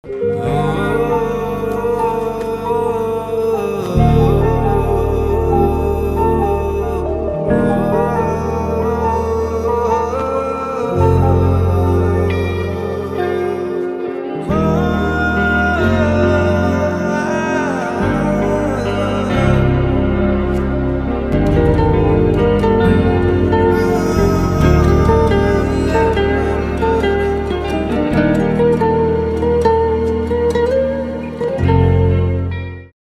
soulful and melodious tune